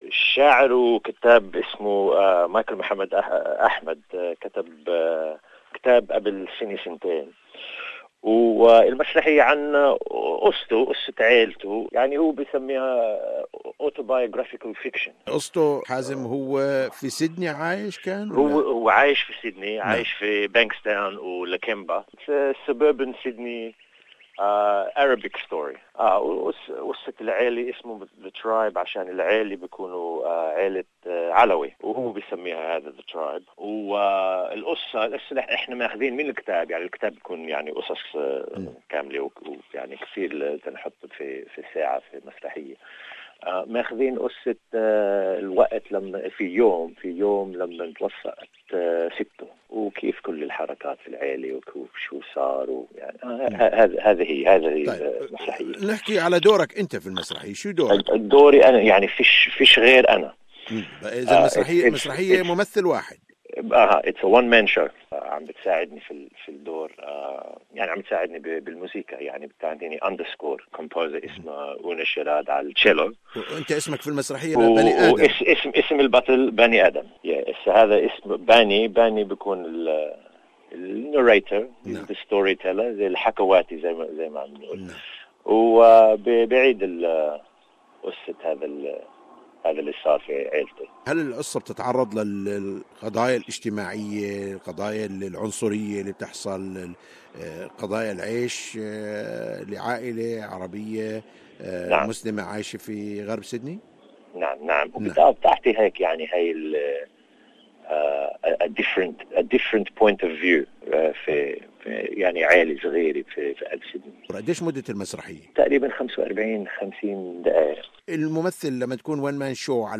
أجرى الحوار التالي